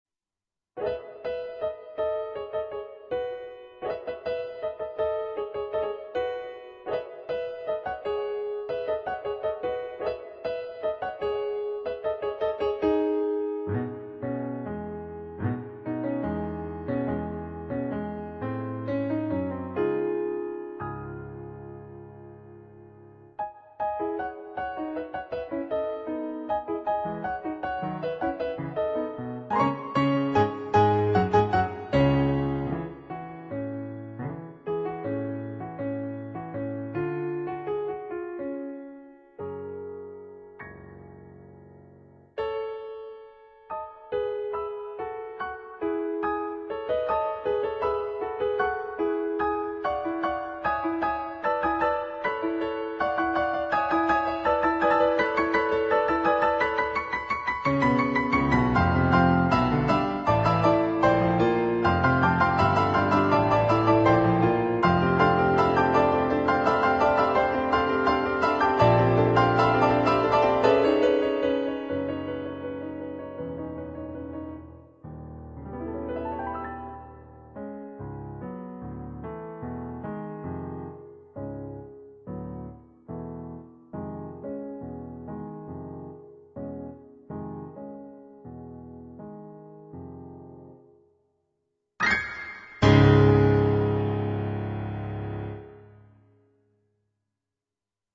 Allegro giocoso (1'38")
on Yamaha digital pianos.